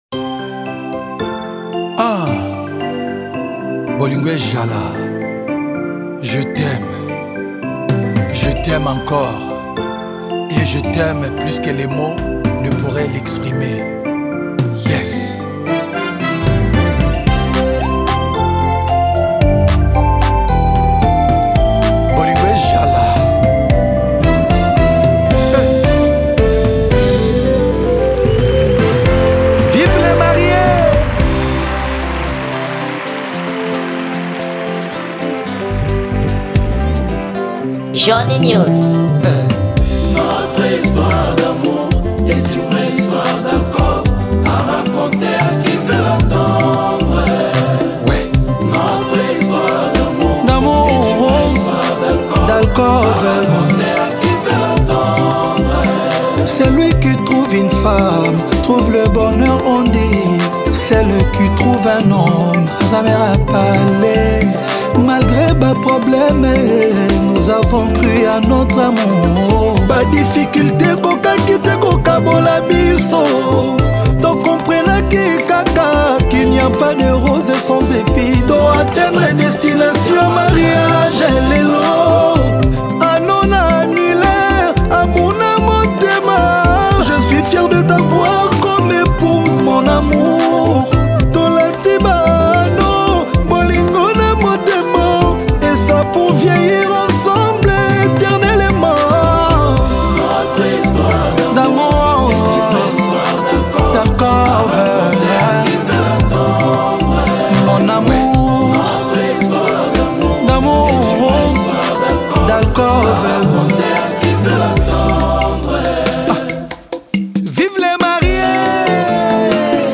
Gênero: Rumba